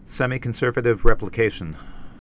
(sĕmē-kən-sûrvə-tĭv, sĕmī-)